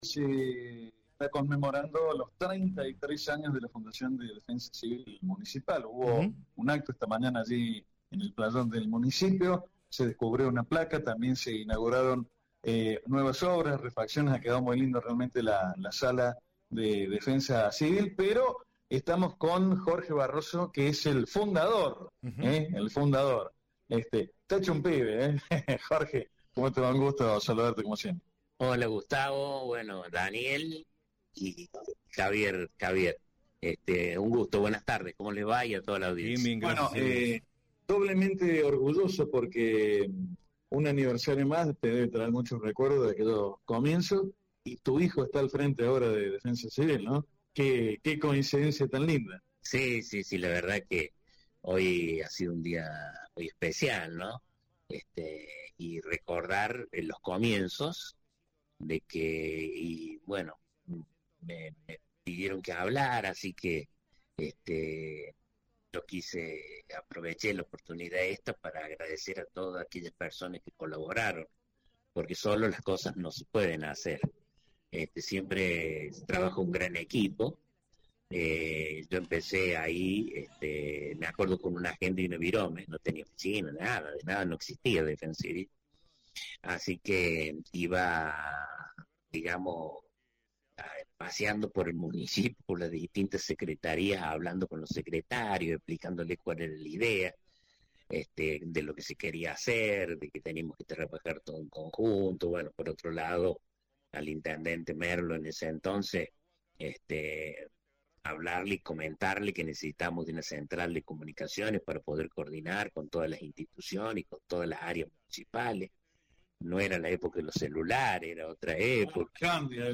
Puntos clave de la entrevista: Esfuerzo pulmón